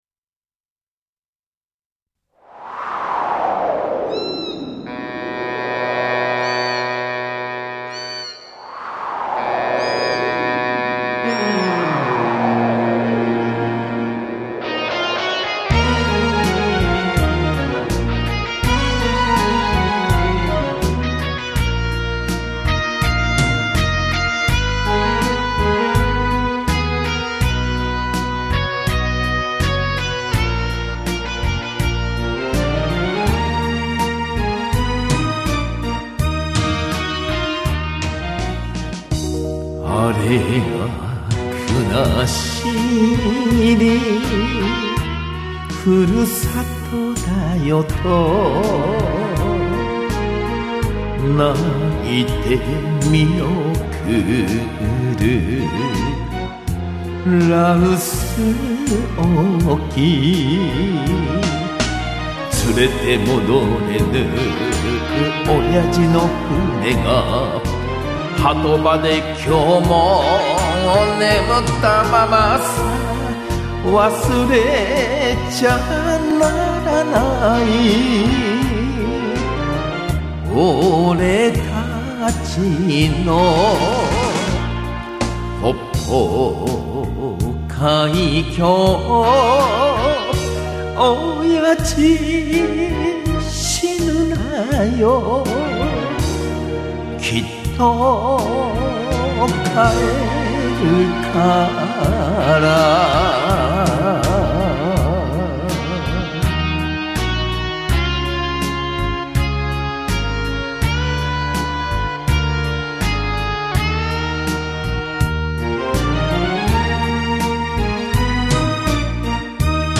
77コンサート　2013/7/7　フレサよしみ